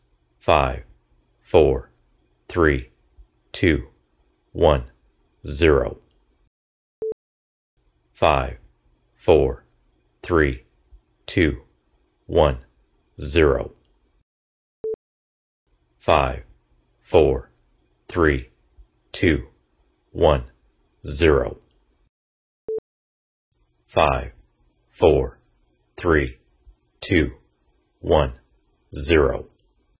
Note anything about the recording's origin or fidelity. Ringing in Conversion from Wav to MP3 We are recording voice educational lectures in wav with an H2 recorder and would like to compress them to place on a website. We notice a bad ringing at the 16000 Hz MP3 joint stereo setting compression in Audacity and there is still some ringing even at 40 kHz.